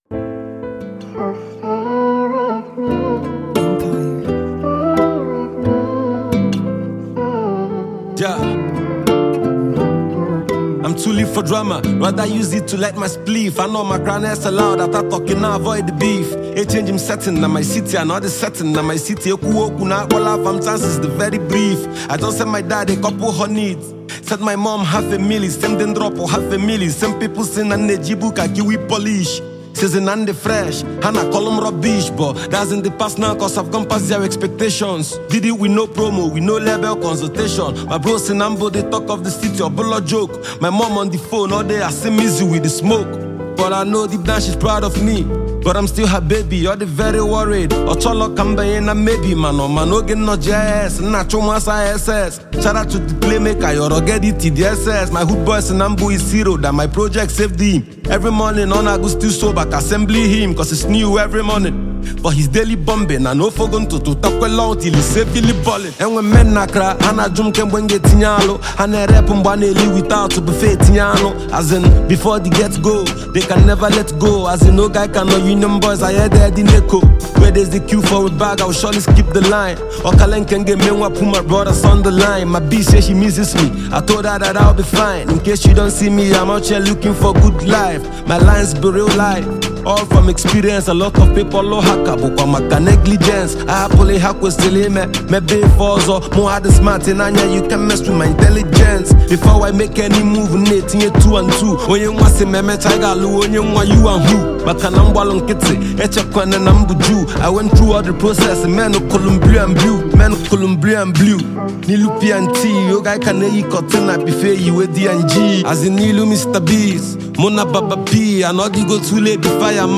Indigenous rapper, singer and songwriter